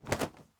Wing Flap 02.wav